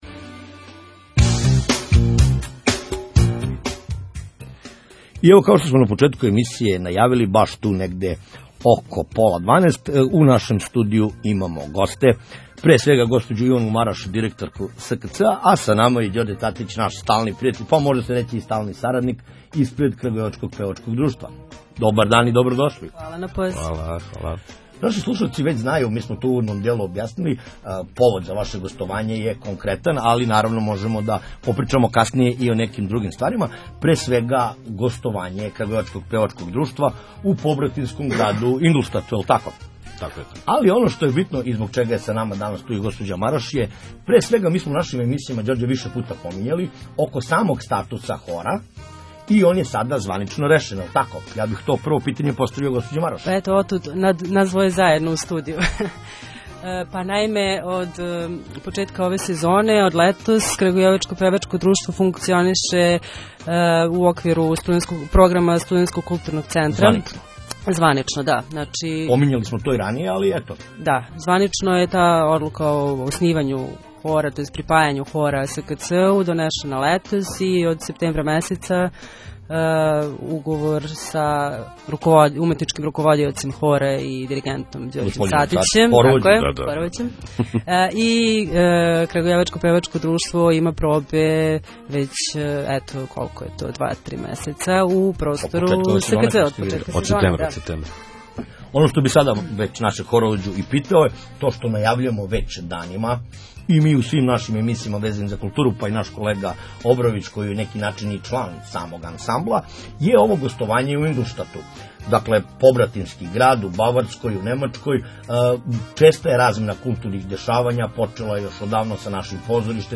INTERVJU-KPD.mp3